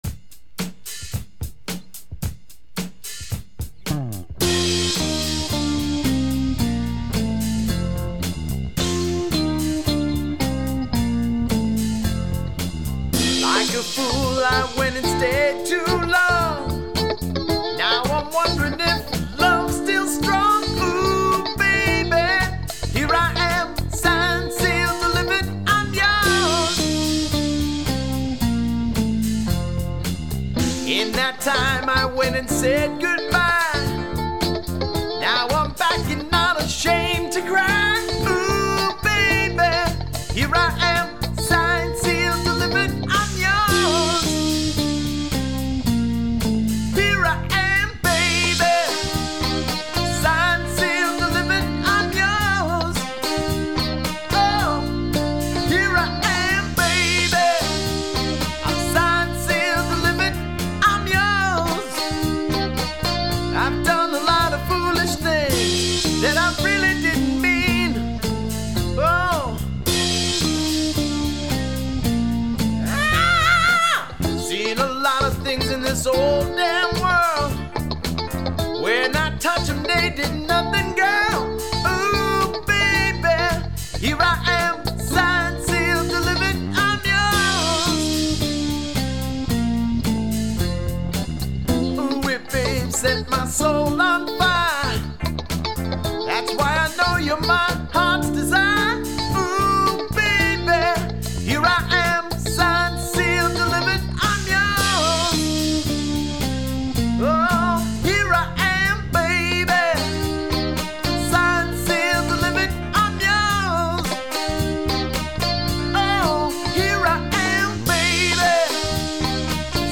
drums
lead vocal
bass